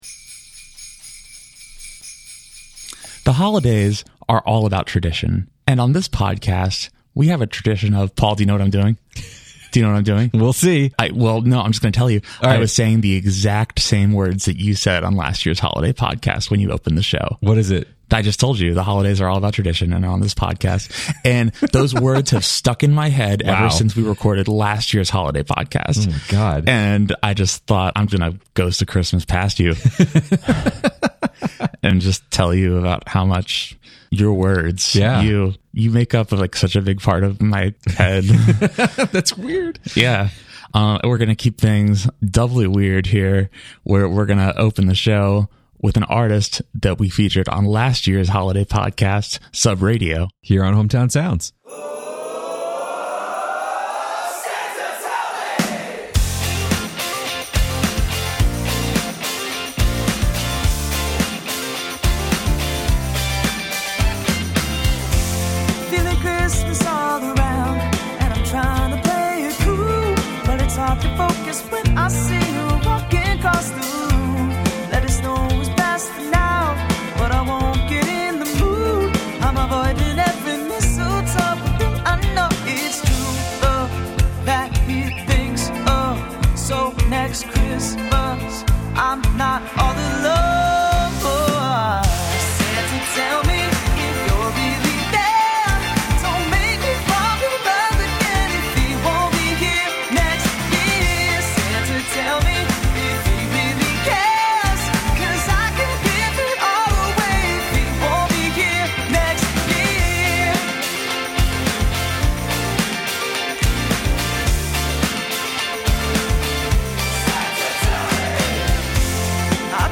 It’s our annual holiday music roundup!